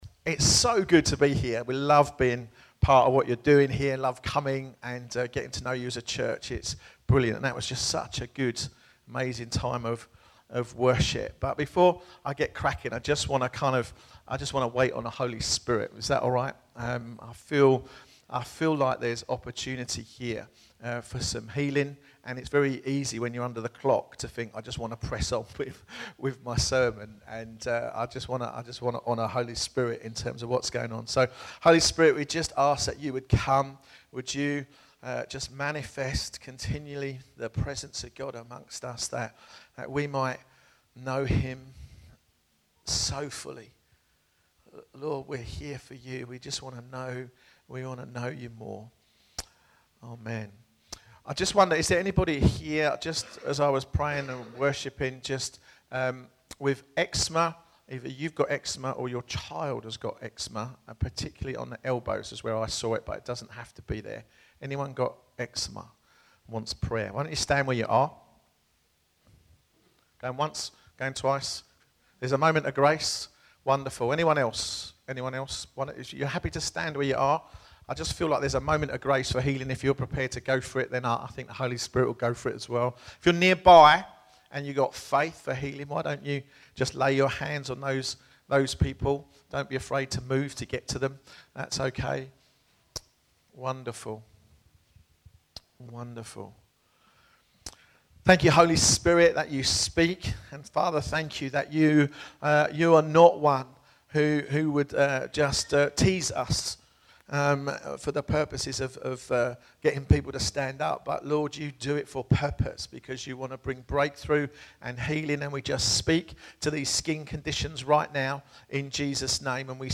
Download Faith - the Evidence of Hope | Sermons at Trinity Church